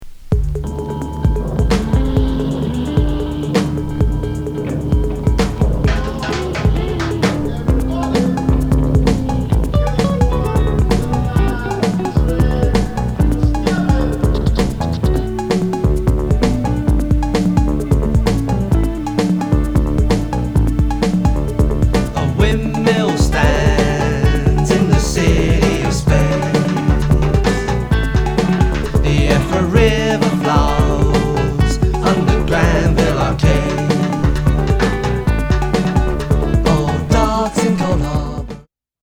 続き、今作はシカゴ・ハウスにデトロイト・テクノも消化。